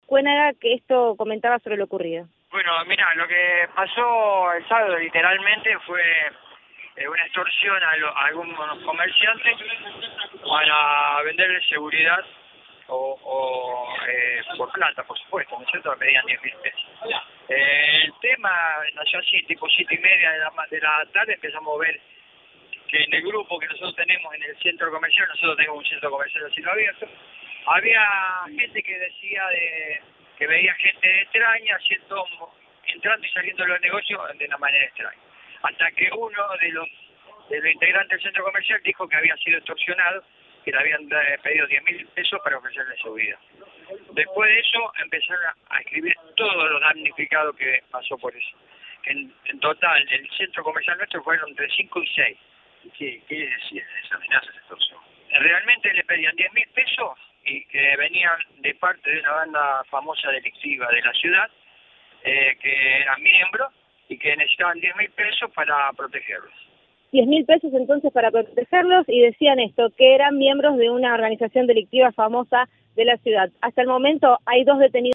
Audio. Comerciante del barrio Azcuénaga de Rosario denuncia extorsión